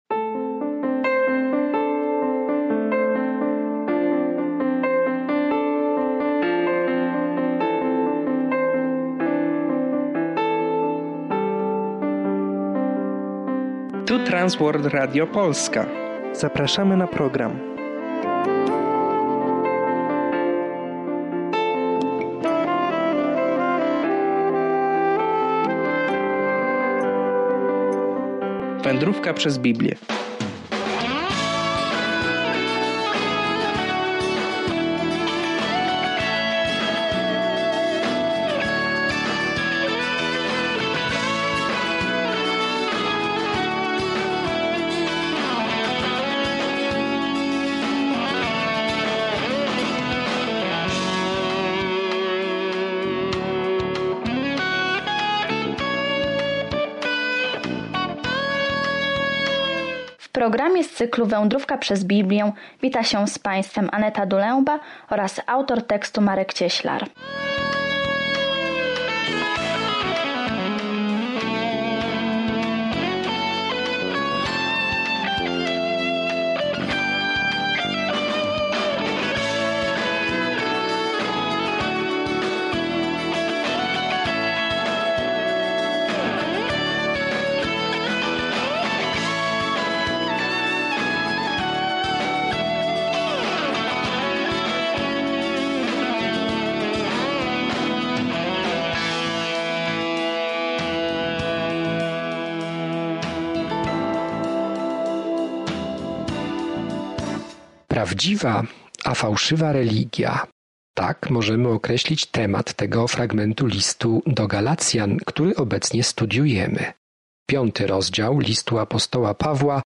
Pismo Święte Galacjan 5:7-15 Dzień 15 Rozpocznij ten plan Dzień 17 O tym planie „Tylko przez wiarę” jesteśmy zbawieni, a nie przez cokolwiek, co czynimy, by zasłużyć na dar zbawienia – takie jest jasne i bezpośrednie przesłanie Listu do Galacjan. Codzienna podróż przez Galacjan, słuchanie studium audio i czytanie wybranych wersetów słowa Bożego.